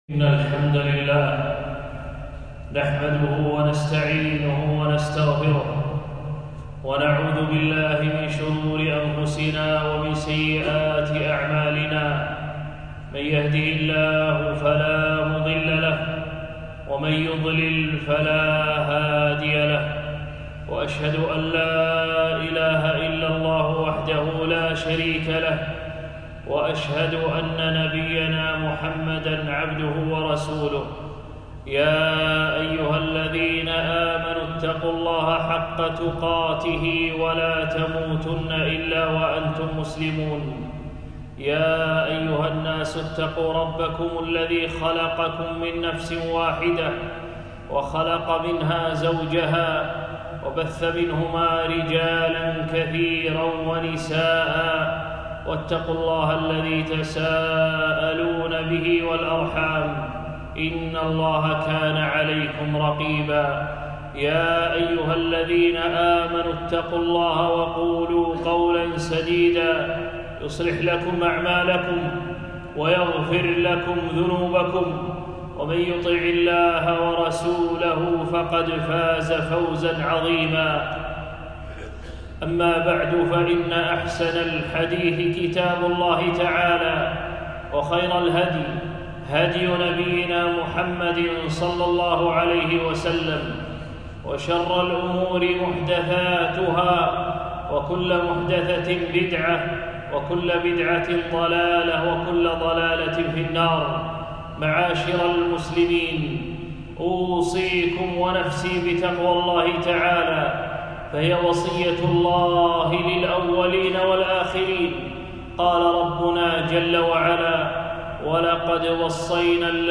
خطبة - حسن الخلق